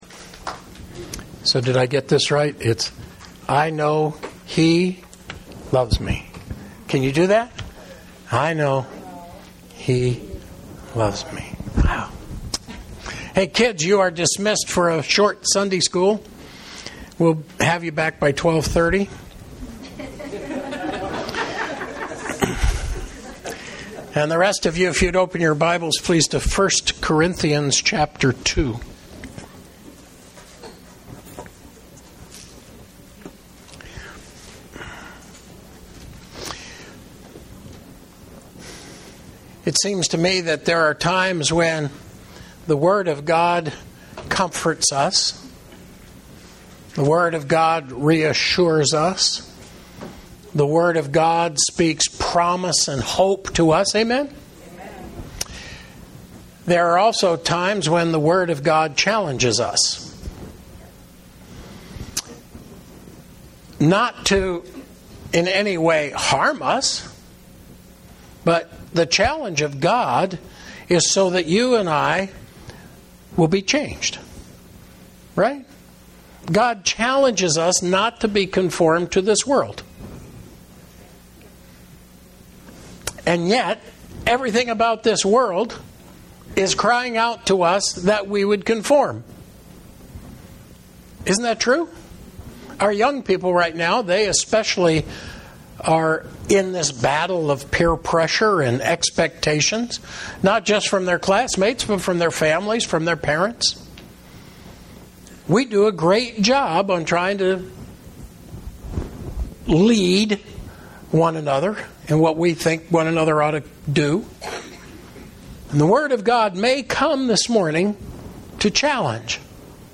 Sunday Morning Message for March 20, 2016